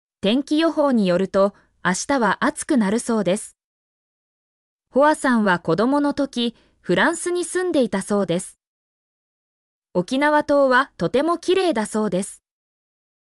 mp3-output-ttsfreedotcom-2_HGebQe6t.mp3